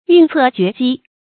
運策決機 注音： ㄧㄨㄣˋ ㄘㄜˋ ㄐㄩㄝˊ ㄐㄧ 讀音讀法： 意思解釋： 同「運籌決策」。